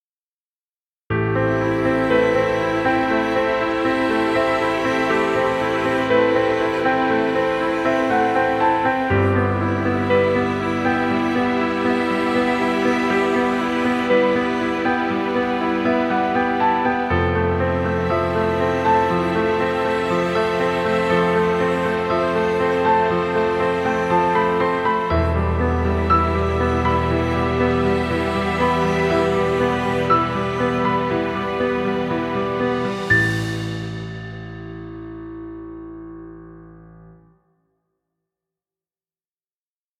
Cinematic romantic msuic. Background music Royalty Free.